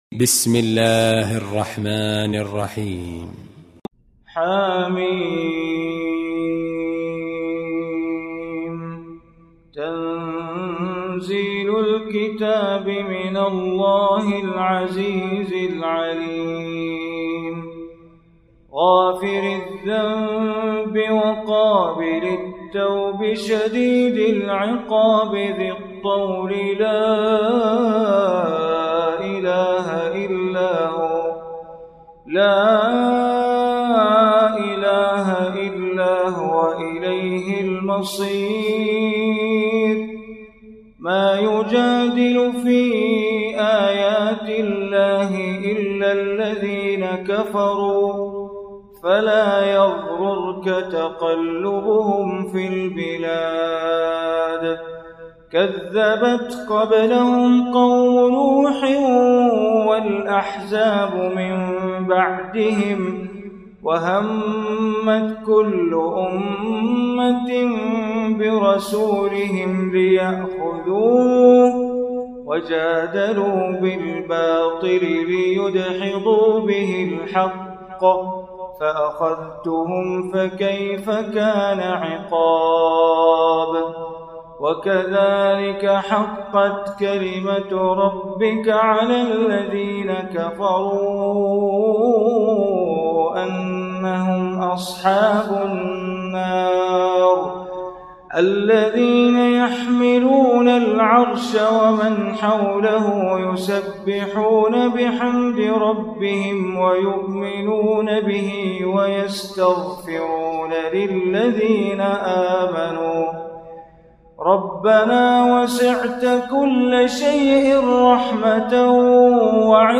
Surah Ghafir Recitation by Sheikh Bander Baleela
Surah Ghafir, listen online mp3 tilawat / recitation in Arabic, recited by Imam e Kaaba Sheikh Bandar Baleela.